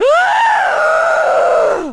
Index of /Downloadserver/sound/zp/zombie/female/
zombi_infection_female_3.mp3